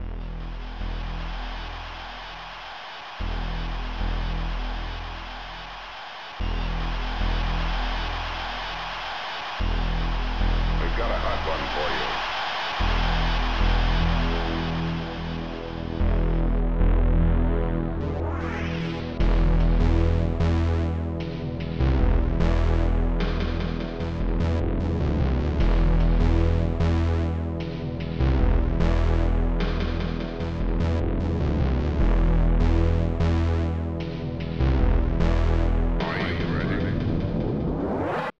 Star Tracker/StarTrekker Module  |  1987-04-22  |  103KB  |  2 channels  |  44,100 sample rate  |  38 seconds
pipe organ
base drum
snare closed
e flute 1
space ship 1
machines
aplause